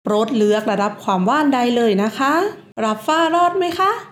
THAI: 3 dialects sound